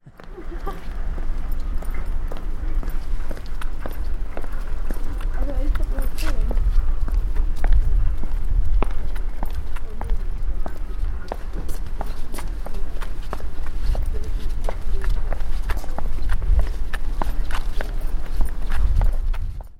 Walking